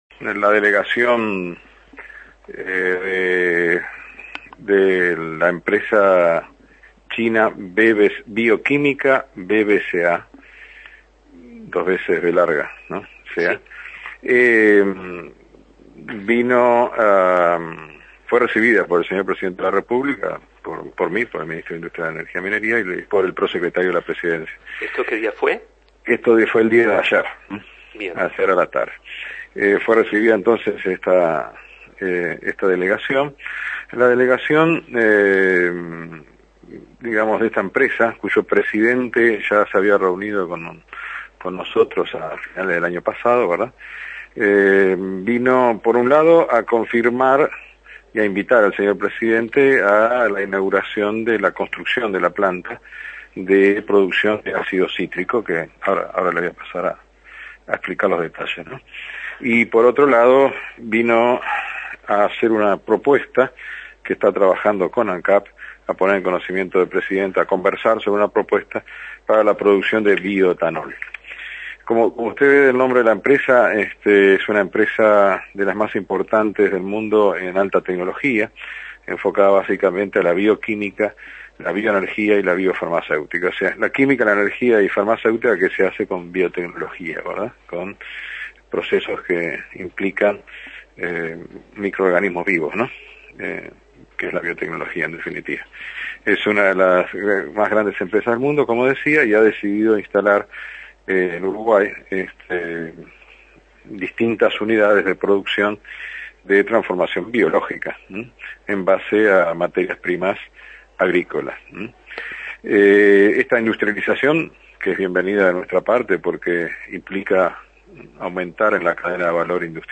Entrevista realizada al Ministro de Industria,Roberto Kreimerman.